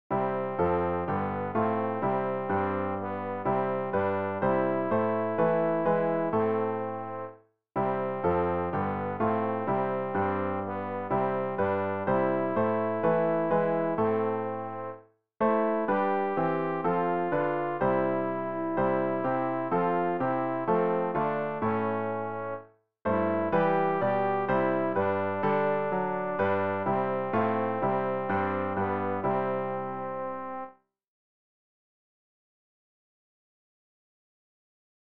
Übehilfen für das Erlernen von Liedern
rg-445-o-haupt-voll-blut-und-wunden-alt.mp3